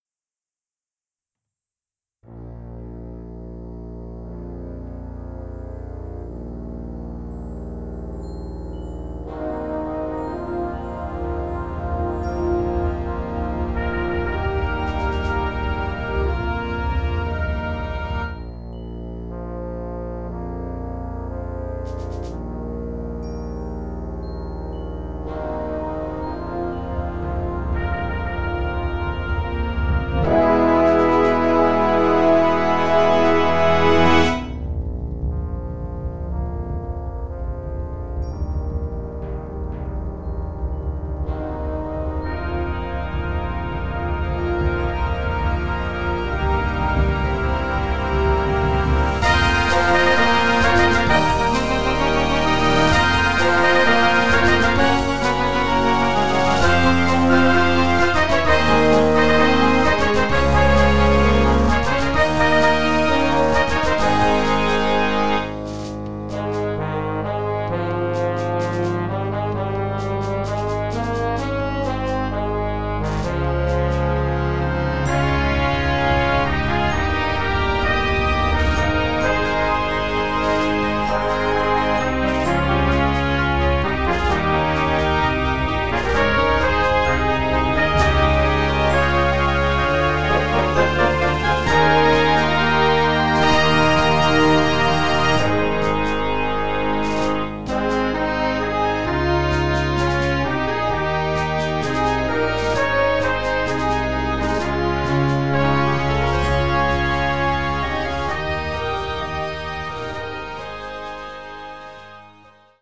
Overture for Concert Band…
Moderne Blasmusik